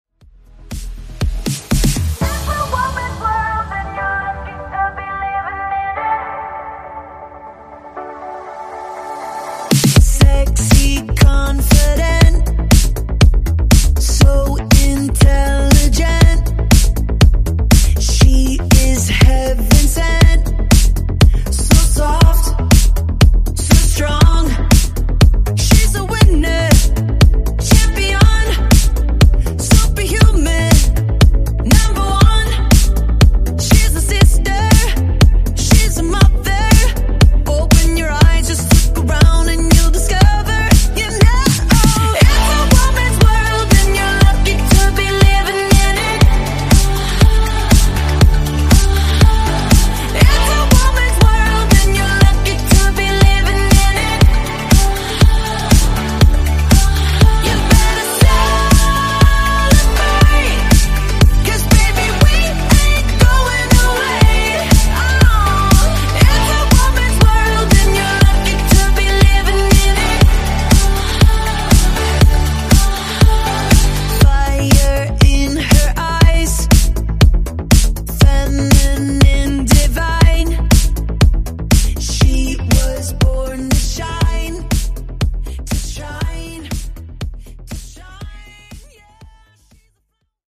Genres: DANCE , EDM , RE-DRUM Version: Clean BPM: 120 Time